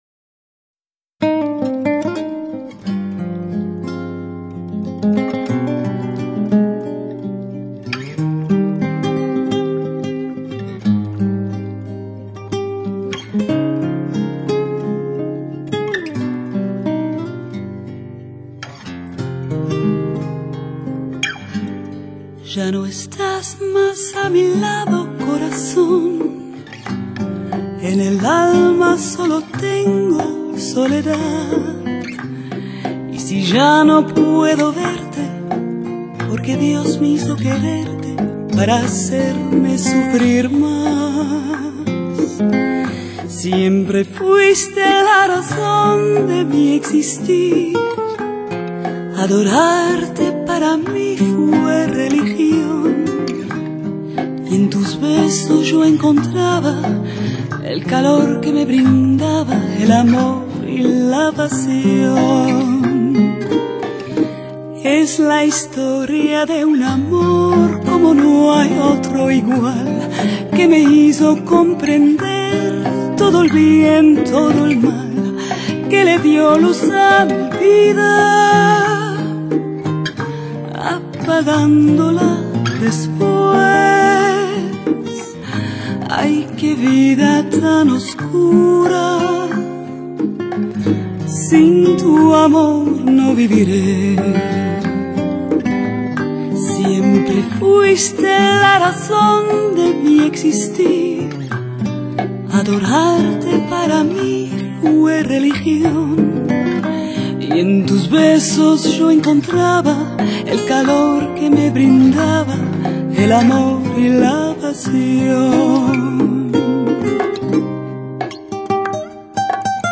拉丁风情的曼妙JAZZ……
平易近人的旋律搭配上她温暖轻松的唱腔，让歌迷们感到温暖贴心和舒适。
火热而纯正的伦巴、恰恰、莎莎、波列罗节奏